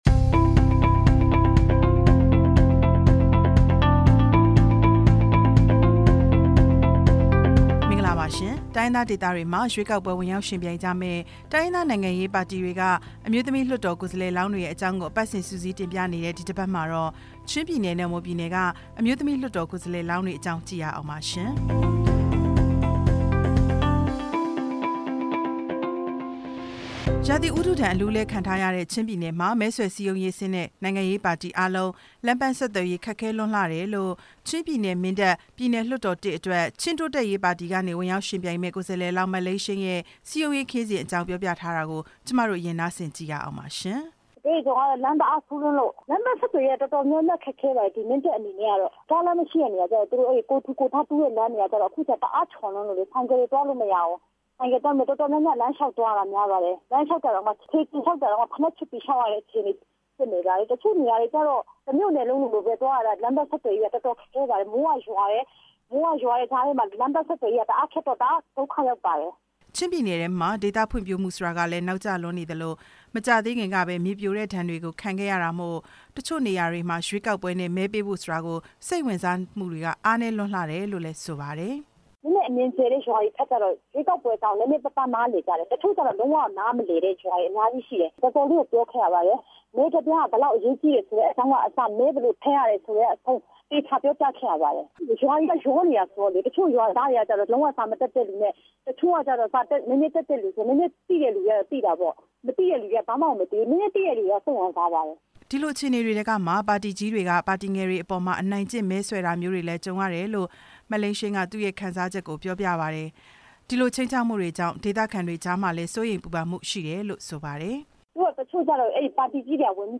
မြန်မာ့ လူ့အခွင့်ရေး အခြေအနေ ဦးဝင်းမြနဲ့ မေးမြန်းချက်